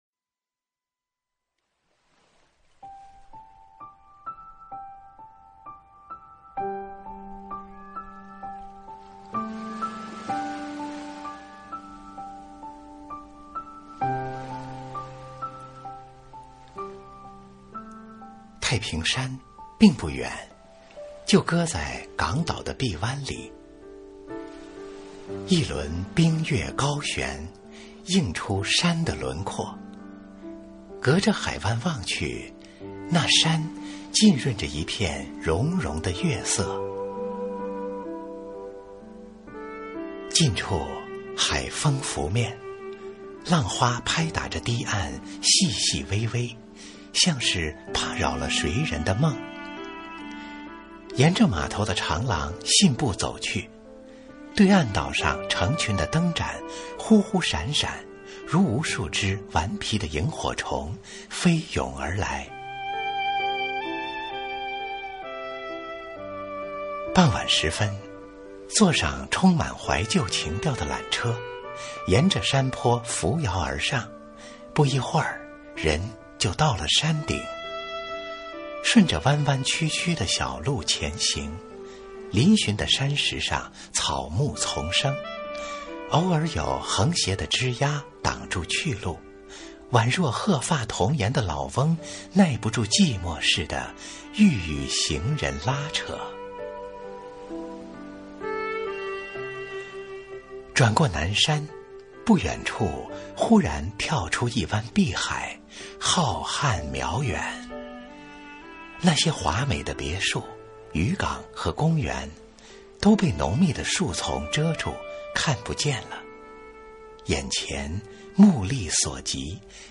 经典朗诵欣赏